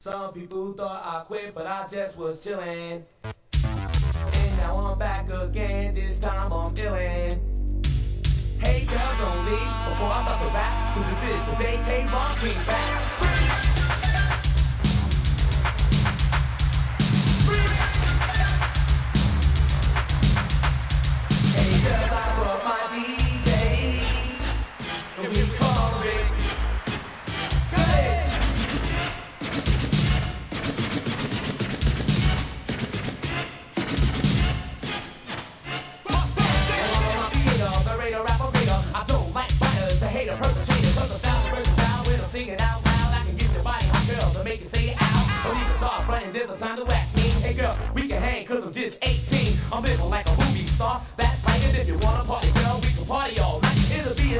HIP HOP/R&B
1985年 OLD SCHOOL!!!